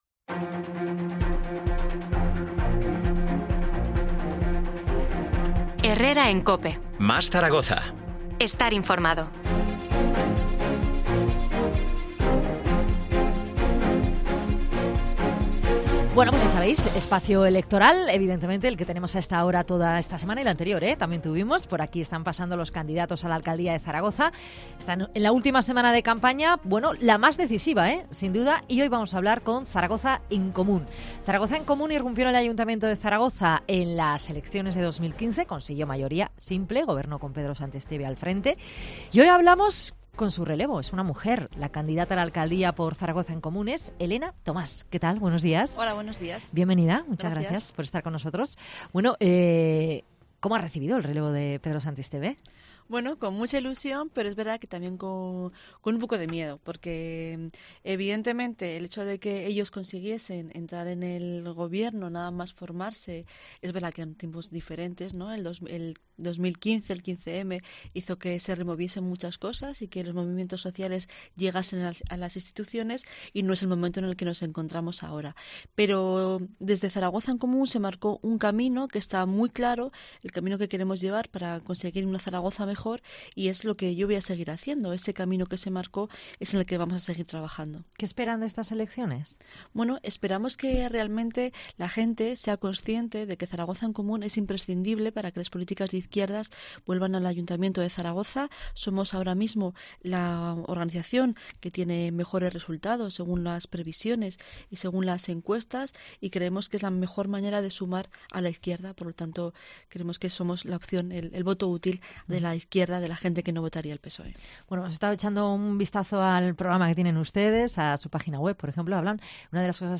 AUDIO: Entrevista a Elena Tomás, candidata a la alcaldía de Zaragoza por Zaragoza en Común